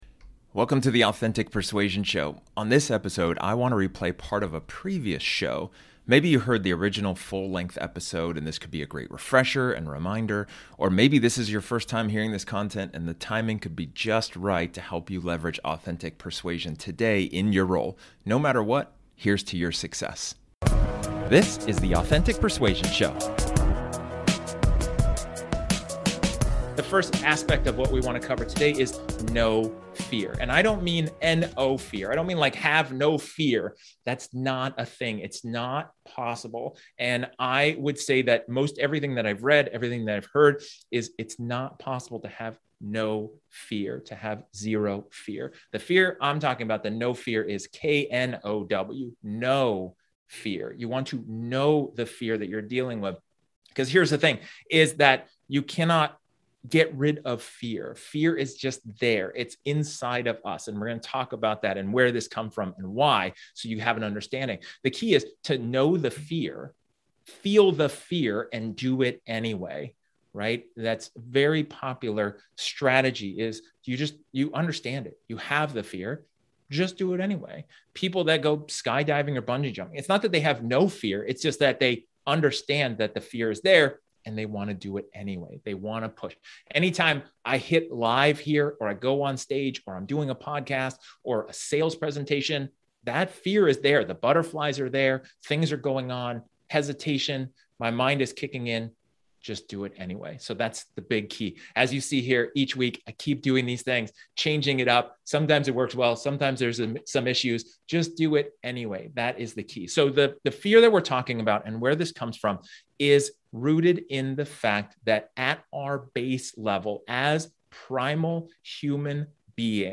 This episode is an excerpt from one of my training sessions where I talk about knowing your fear and why it is important for salespeople to succeed.